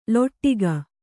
♪ loṭṭiga